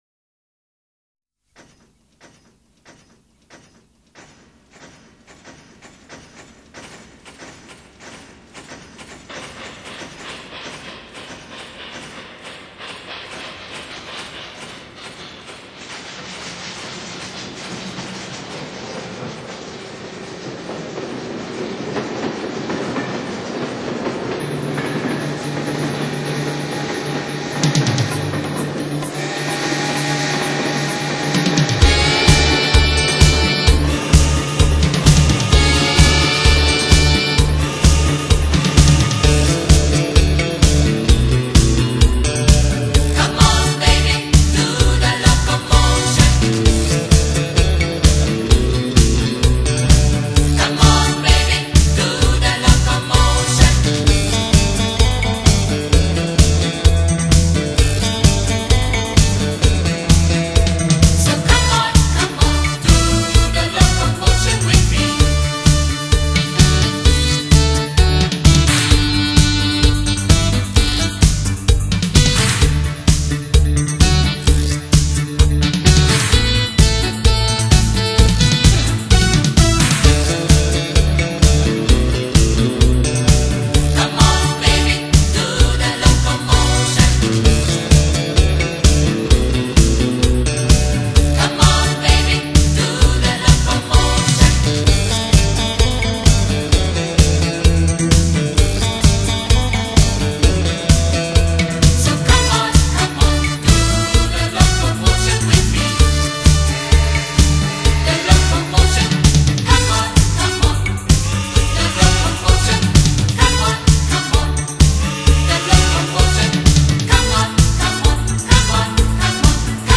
精彩绕场超炫音效
西洋发烧金曲 热情串烧45转
绕场立体音效 发烧音乐重炫